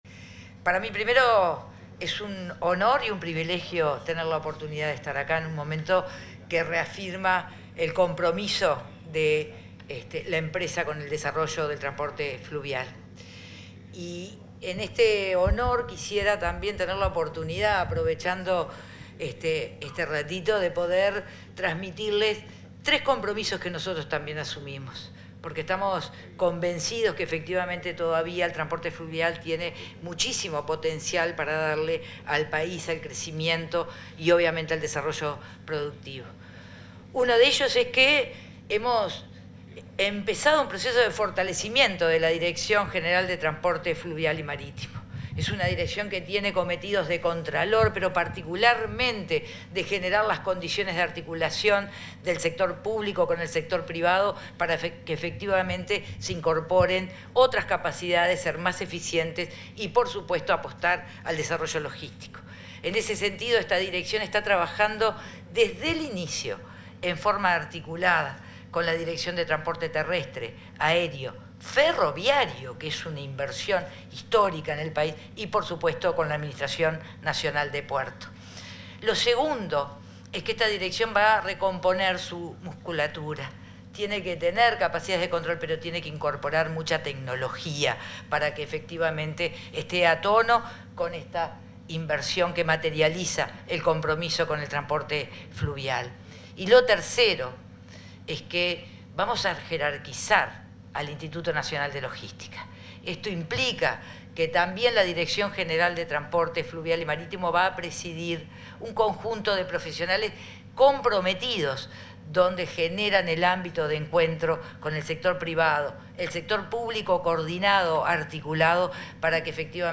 Palabras de la ministra de Transporte y Obras Públicas, Lucía Etcheverry, en ocasión de la inauguración del remolcador TFF Anglo, en Fray Bentos.